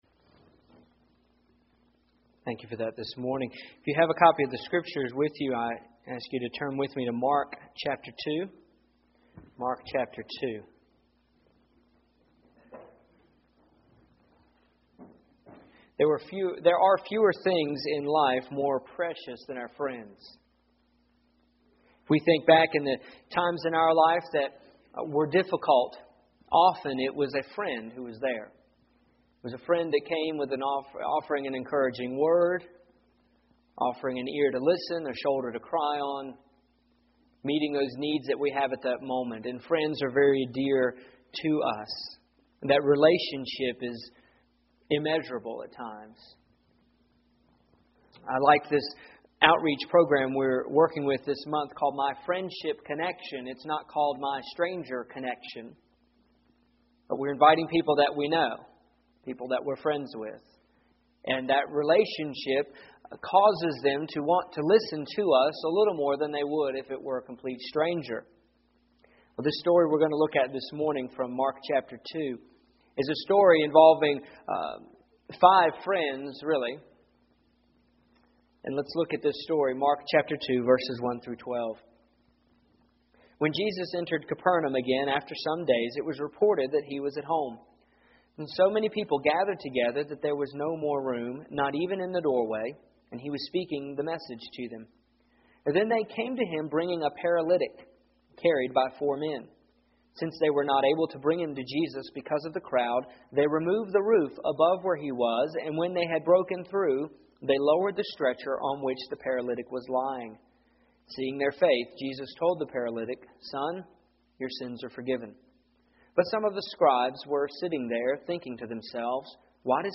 Here is my sermon from yesterday, October 26th